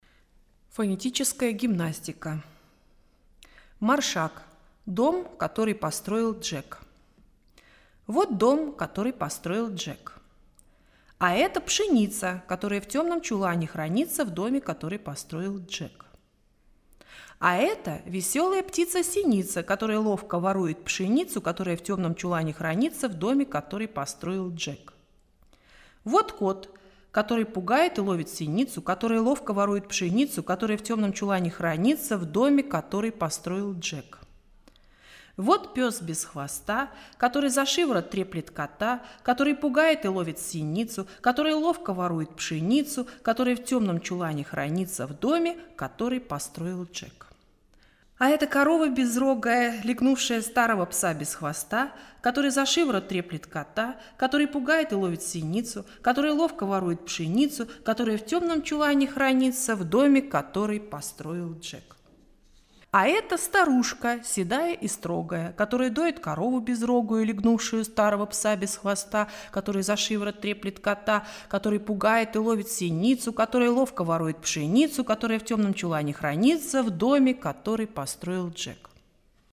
Poslechová cvičení
Дом, который построил Джек (Фонетическая гимнастика) – 1:20